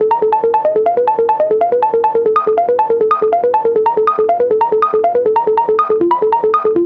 synth line is just a loop from a sample pack that I shortened, molested with OTT, delay and tons of reverb send and shifted its beginning by two quarter notes: